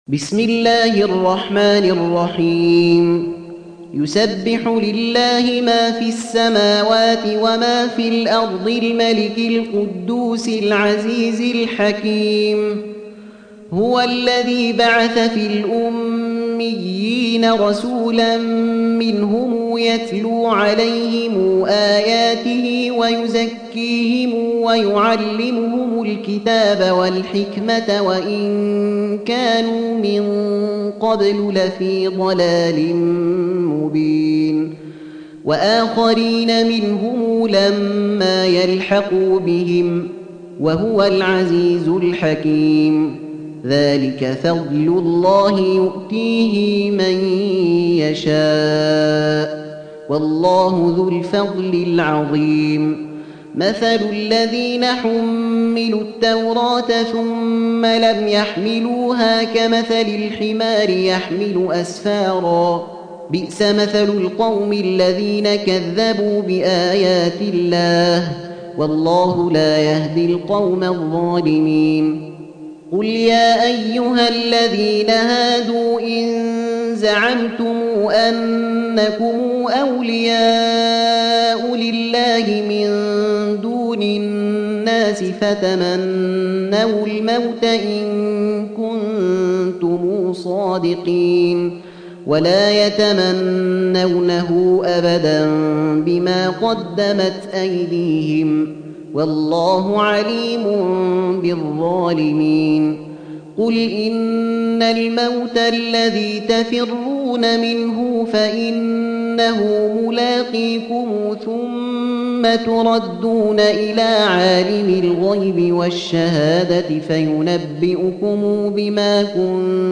Surah Sequence تتابع السورة Download Surah حمّل السورة Reciting Murattalah Audio for 62. Surah Al-Jumu'ah سورة الجمعة N.B *Surah Includes Al-Basmalah Reciters Sequents تتابع التلاوات Reciters Repeats تكرار التلاوات